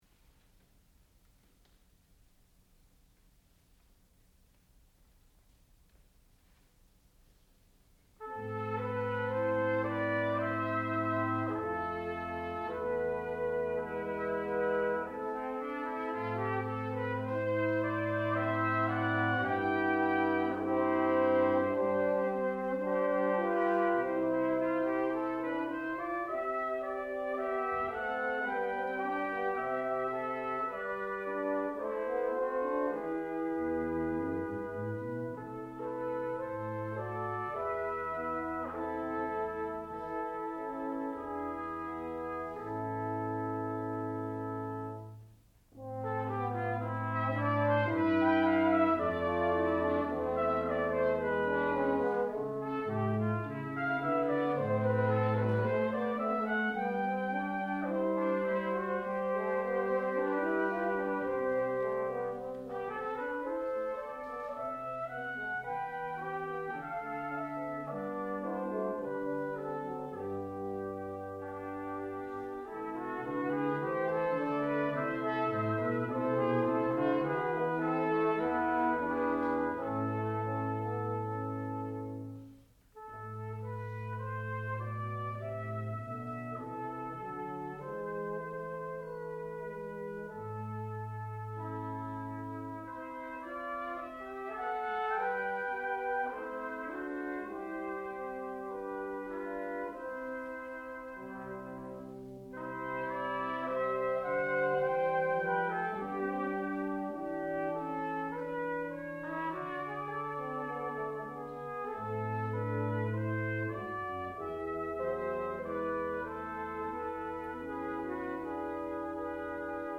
sound recording-musical
classical music
The Shepherd Brass Quintet (performer).